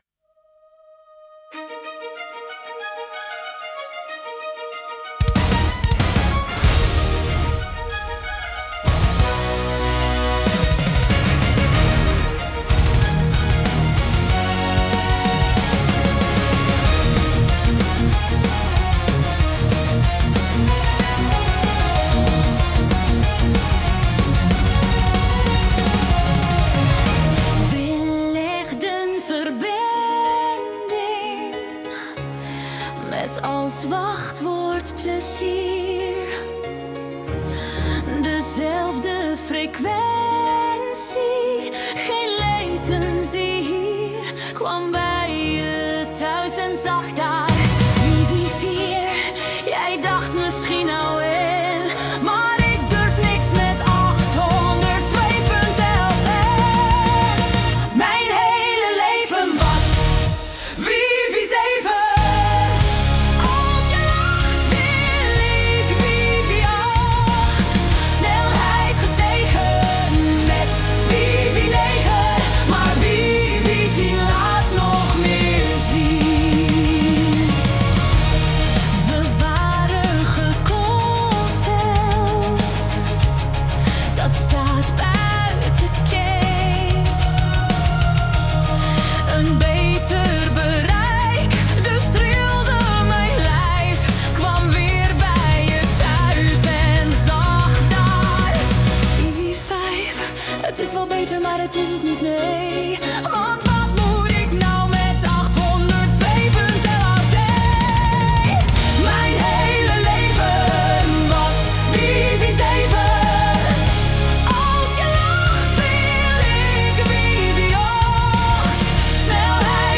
Ben ergens ook wel benieuwd naar een ouderwetse symphonic metal (jaren "90 tot 2010 ofzo, dat was wat atmospheric en minder bombastisch) met een vrouwelijke hoge operastem (zijn synthesizers over het algemeen goed in, iig makkelijker dan "normale" stemmen) lijkt me ook wel grappig.